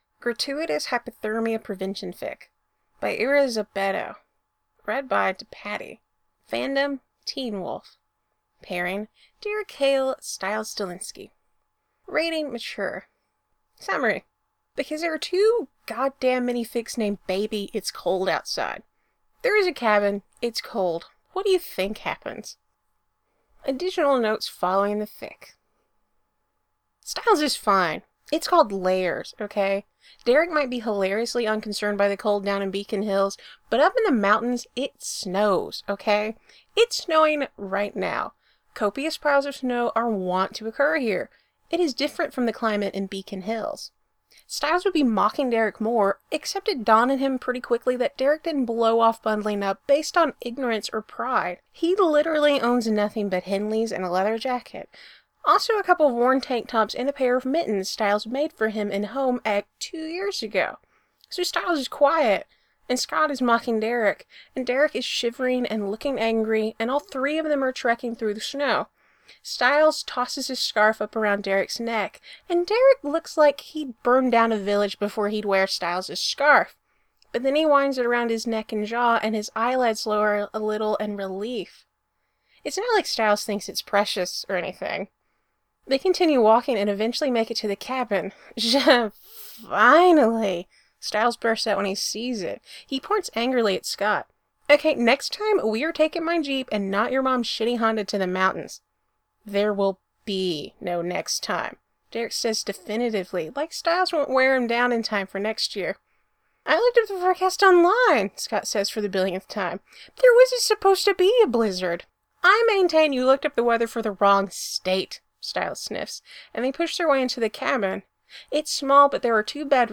Teen Wolf Podfic: Gratutious Hypothermia Prevention Fic